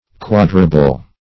quadrible - definition of quadrible - synonyms, pronunciation, spelling from Free Dictionary
quadrible - definition of quadrible - synonyms, pronunciation, spelling from Free Dictionary Search Result for " quadrible" : The Collaborative International Dictionary of English v.0.48: Quadrible \Quad"ri*ble\, a. Quadrable.